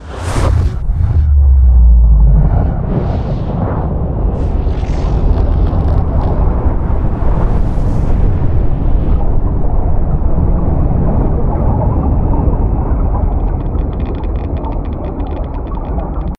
File:AuroraExplosion.ogg